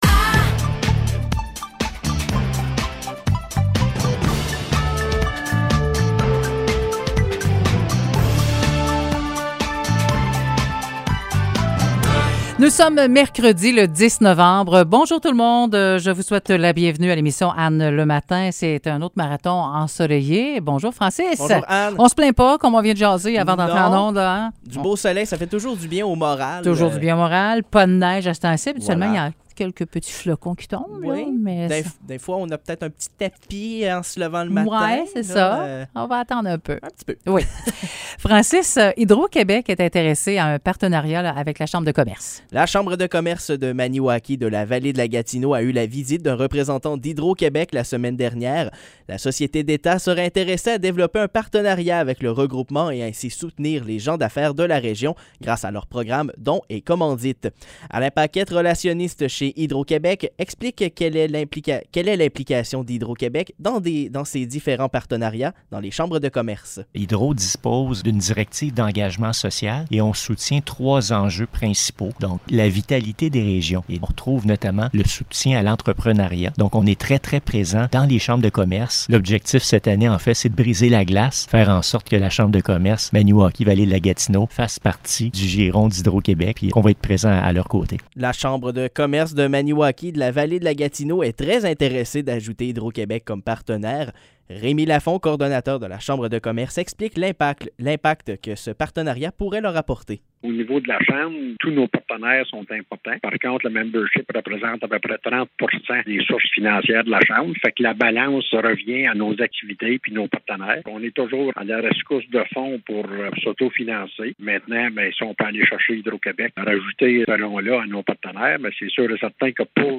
Nouvelles locales - 10 novembre 2021 - 9 h